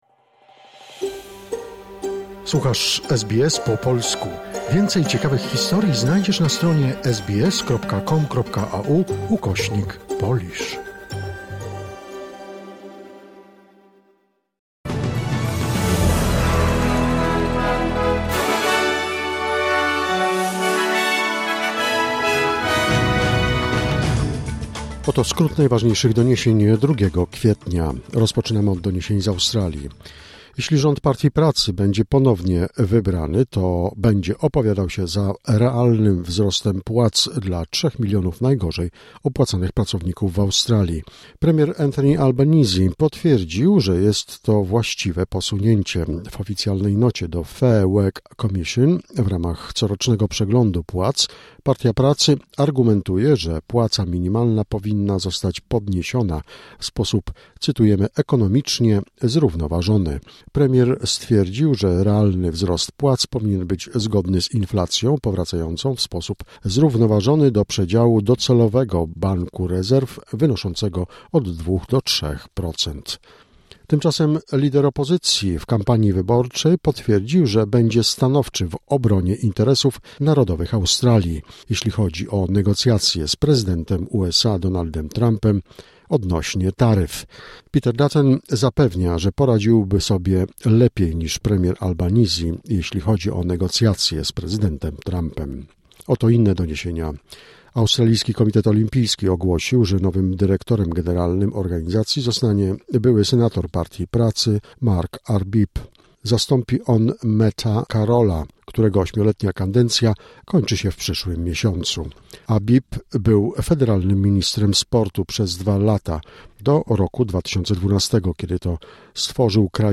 Wiadomości 2 kwietnia SBS News Flash